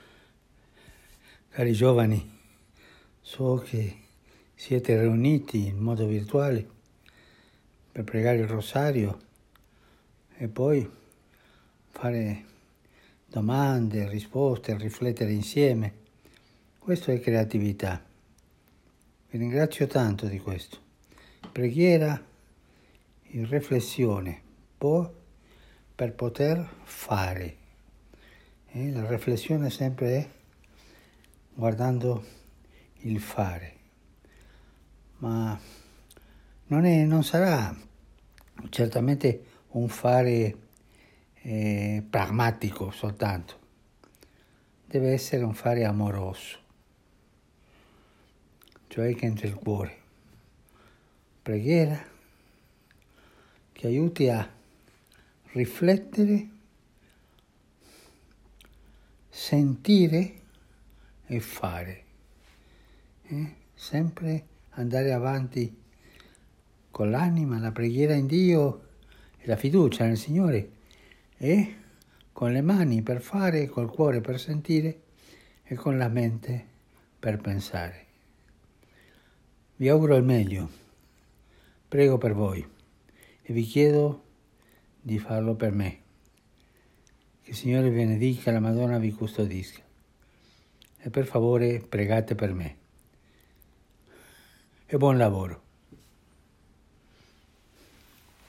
Anche Papa Francesco, infatti, è intervenuto con un messaggio vocale registrato per l’occasione.